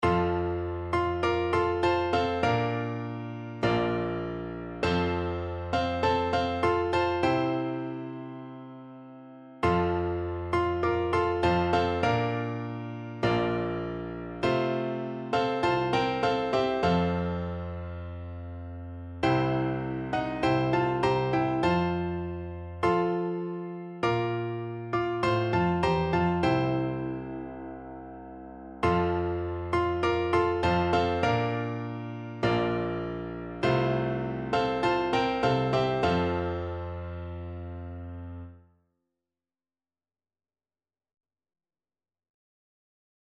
4/4 (View more 4/4 Music)
Piano Duet  (View more Easy Piano Duet Music)
Classical (View more Classical Piano Duet Music)